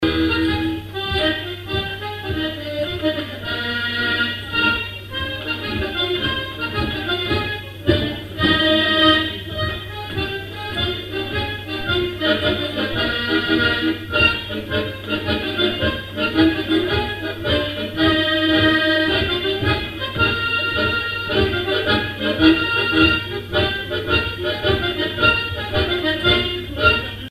danse : pas de quatre
airs pour animer un bal
Pièce musicale inédite